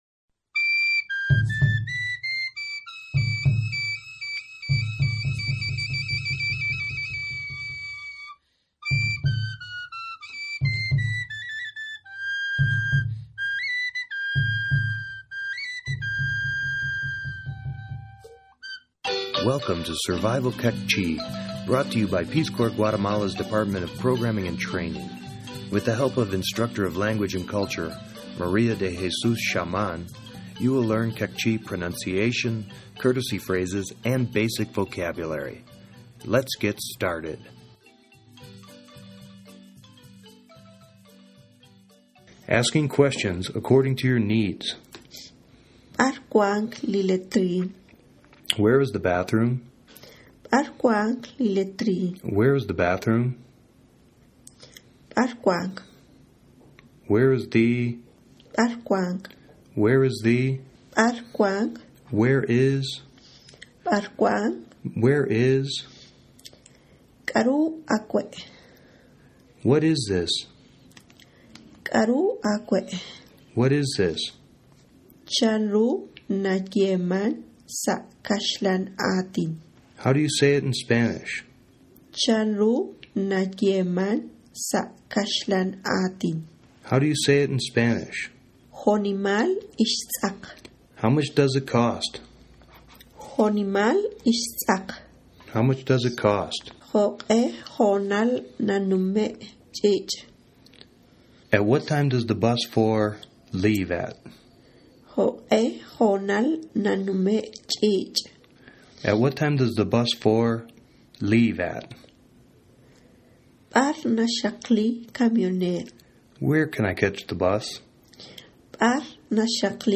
Survival Queqchi - Lesson 05 - Questions, Basic Information, Money_.mp3